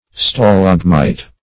Stalagmite \Sta*lag"mite\ (st[.a]*l[a^]g"m[imac]t), n. [Gr.